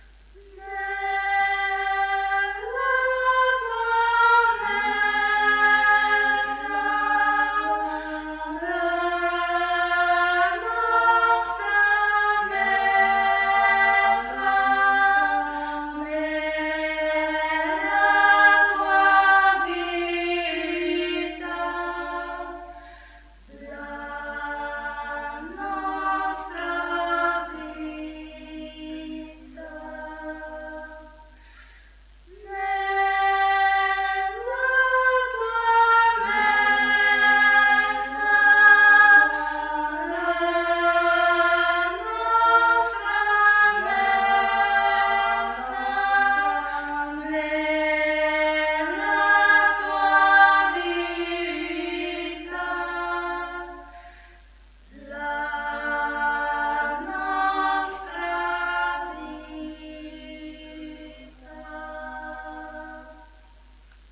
Coretto parrocchiale "Les Mariutines" di Tomba
GUARDA QUESTA OFFERTA -  Canto religioso